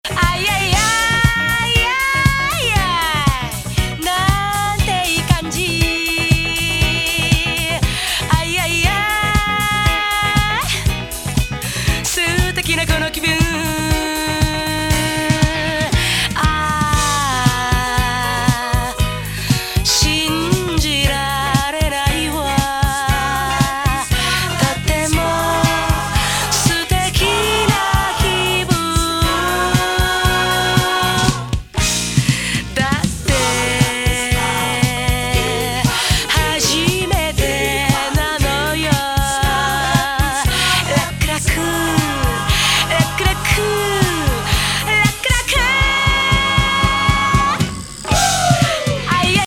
日本語スムース・ディスコ・カバーがナイス!!